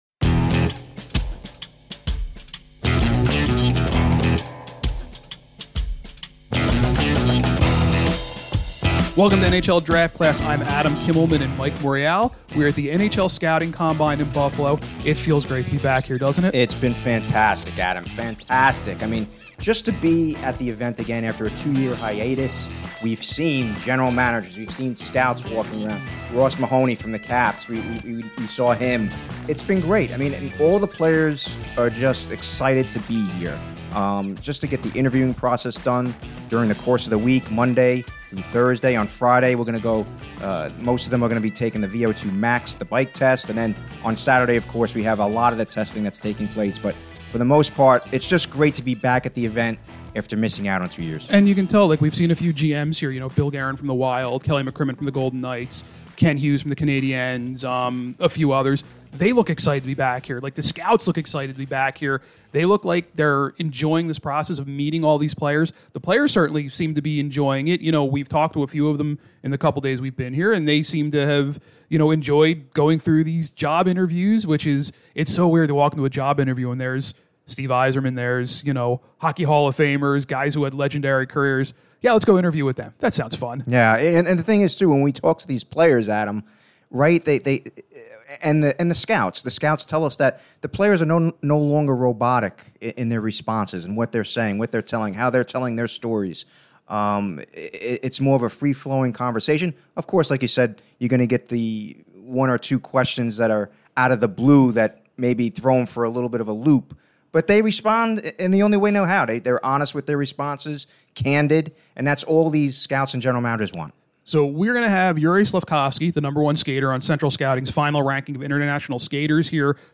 NHL Draft Class records from the NHL Scouting Combine in Buffalo